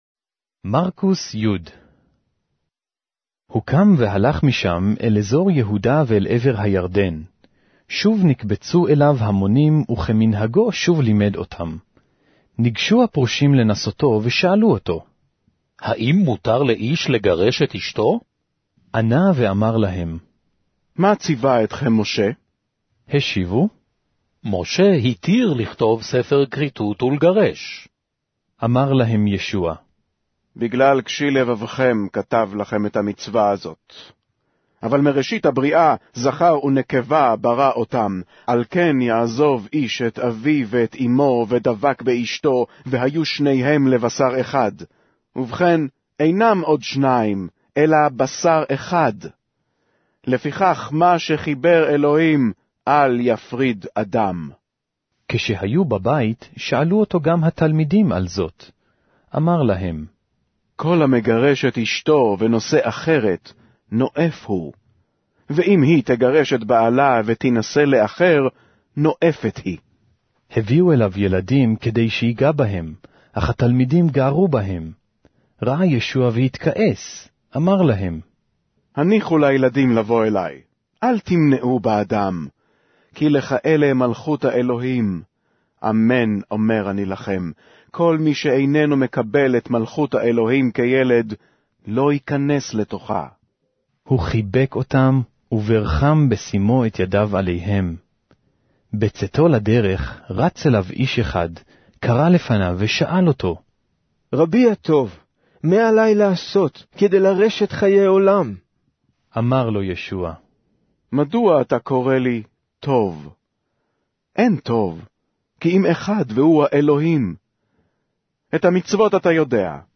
Hebrew Audio Bible - Mark 15 in Knv bible version